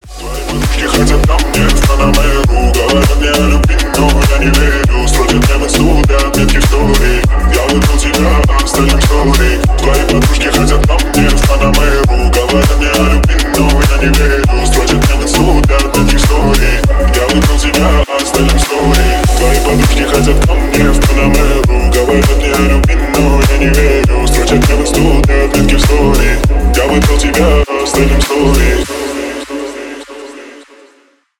Ремикс # Поп Музыка # Рэп и Хип Хоп